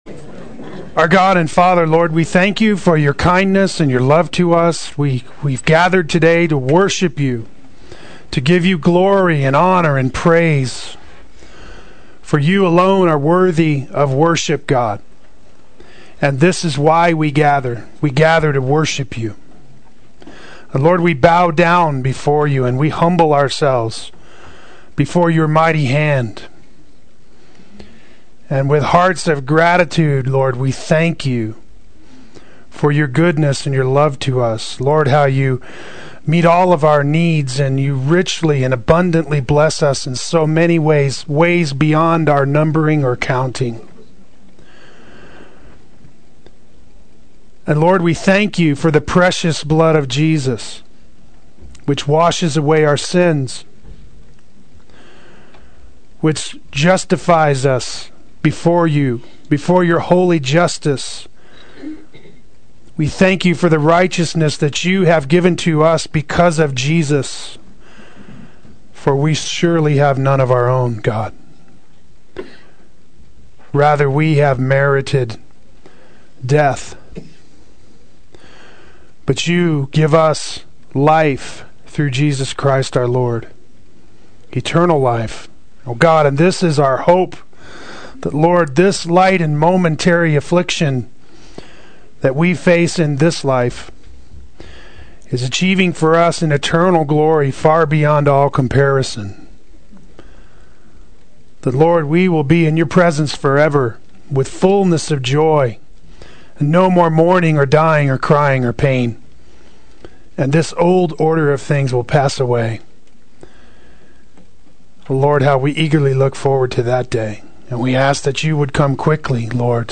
An Essential Family Value Adult Sunday School